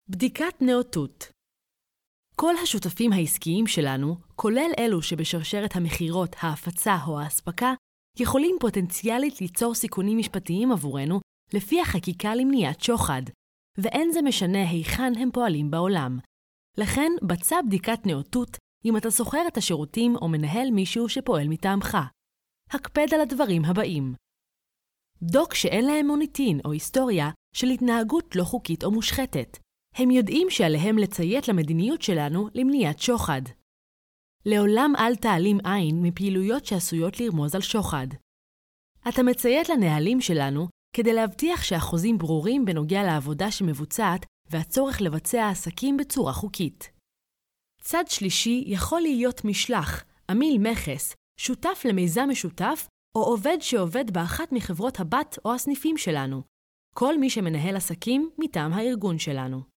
Voice Overs
HE AS EL 01 eLearning/Training Female Hebrew